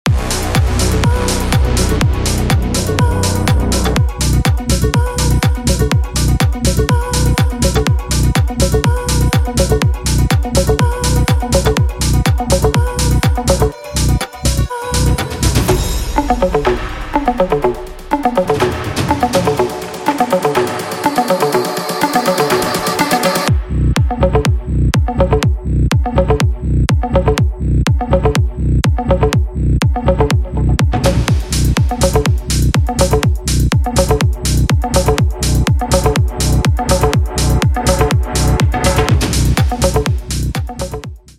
High-Intensity Techno Track
With its driving beats and captivating soundscapes